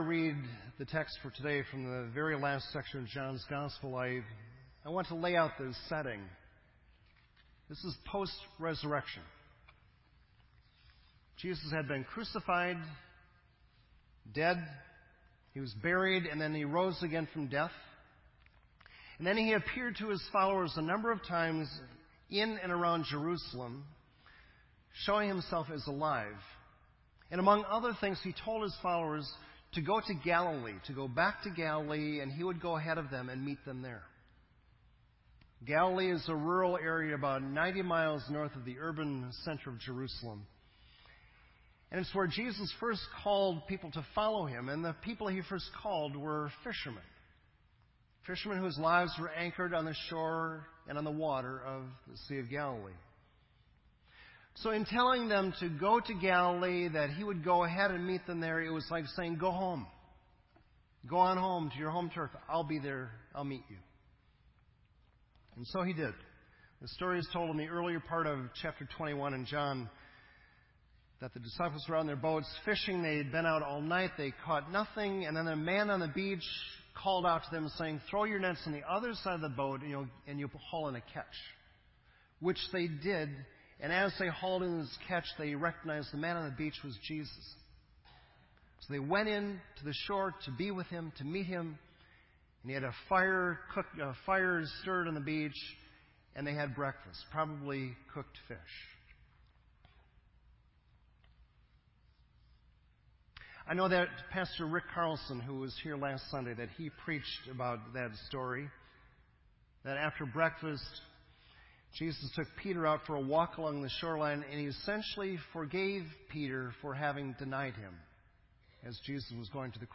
This entry was posted in Sermon Audio on April 19